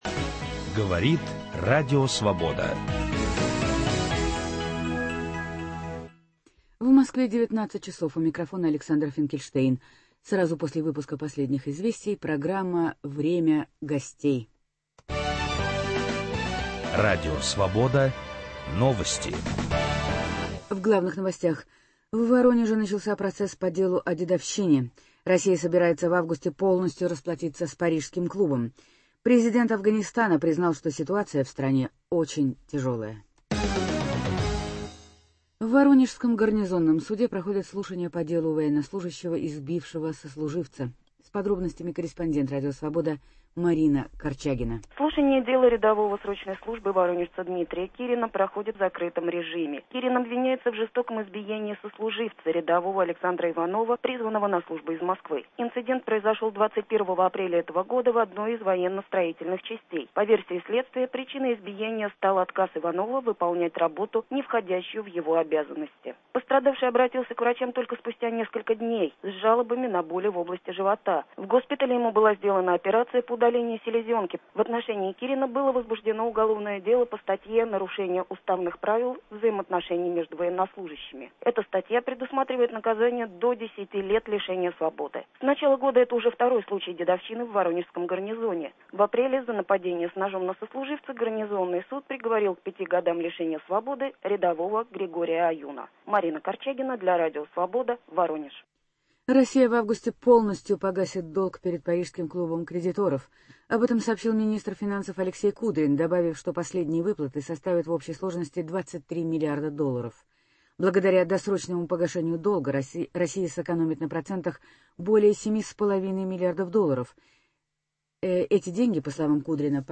Гость Московской студии – глава Архиепархии Божьей Матери в Москве, глава российских католиков, митрополит Тадеуш Кондрусевич.